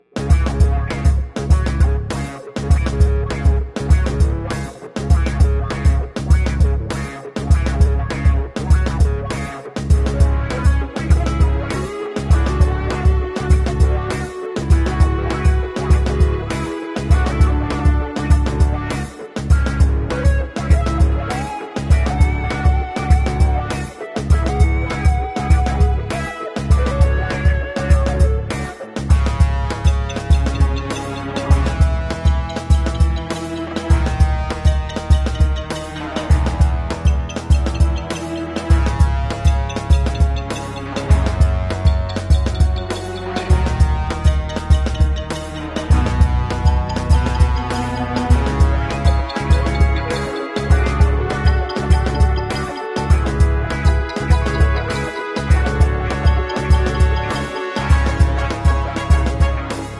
Luke Cage Crushes Steel With Sound Effects Free Download